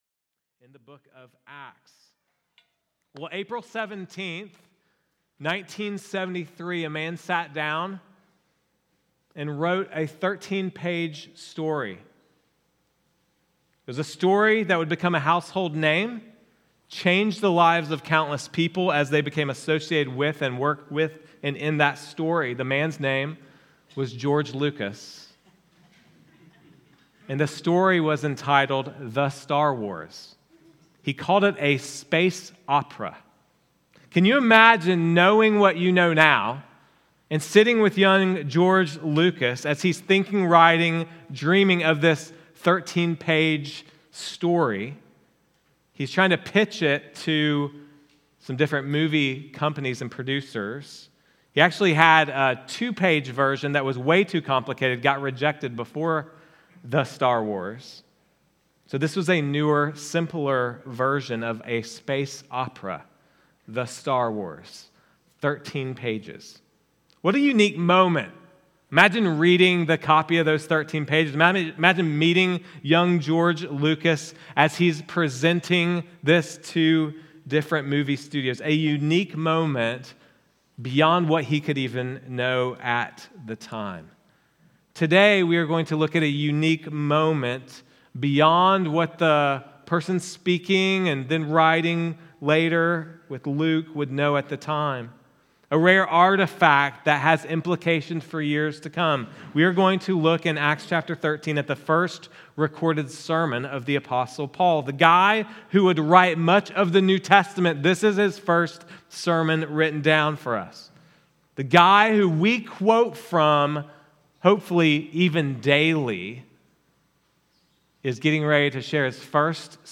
Sermons | Risen Hope Church